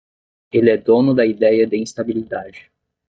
/ĩs.ta.bi.liˈda.d͡ʒi/